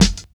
62 SNARE 4.wav